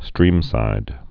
(strēmsīd)